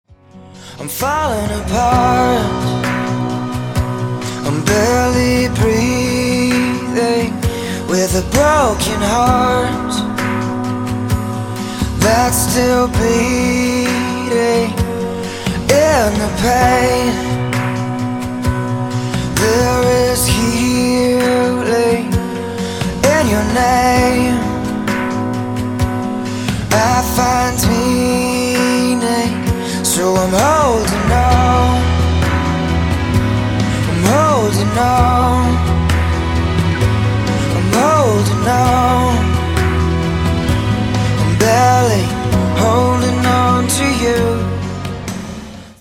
• Качество: 192, Stereo
мужской вокал
спокойные
поп-рок